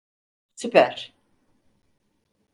Pronunciat com a (IPA) /sy.pæɾ/